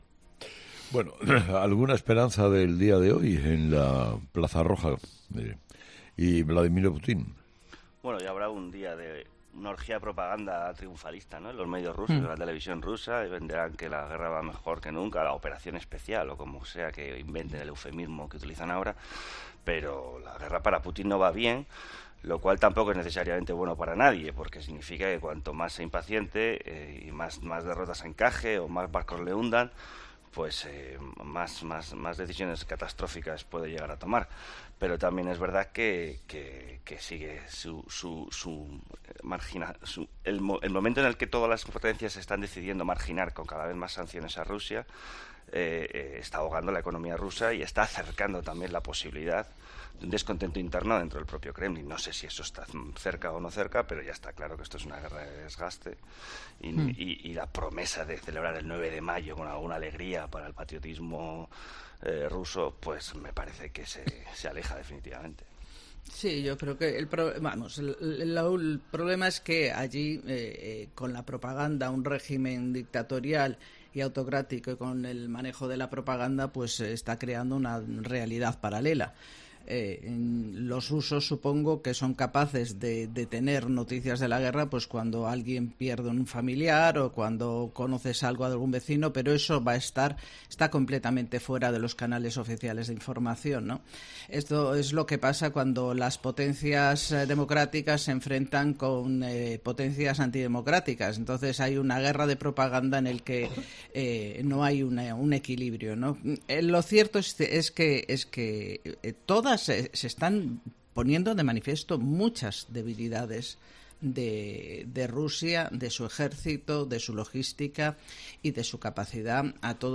Desde el café de redacción de 'Herrera en COPE' se ha querido responder a la pregunta: ¿qué va a pasar este lunes 9 de mayo?